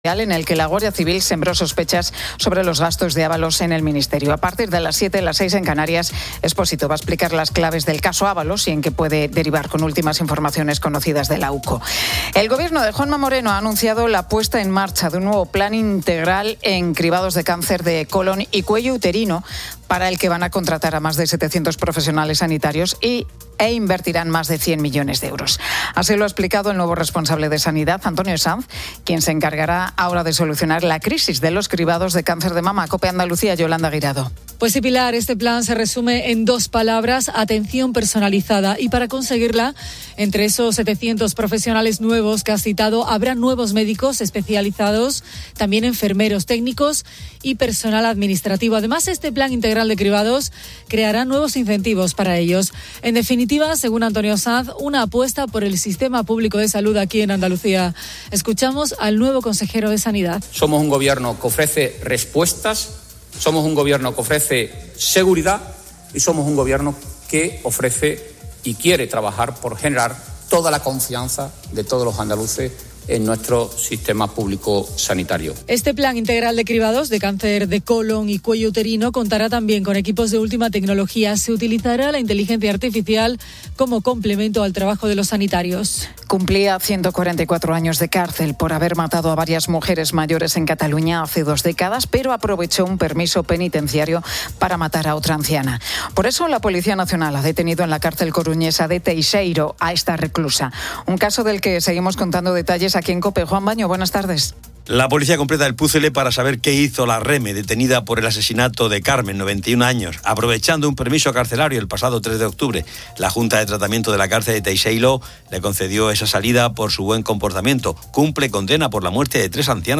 La Tarde 18:00H | 15 OCT 2025 | La Tarde Pilar García Muñiz presenta la historia de Javi Nieves: los bomberos de rescate que entrenan, educan y conviven con sus perros especializados.